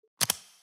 Звук завершения видеозвонка в Фейстайм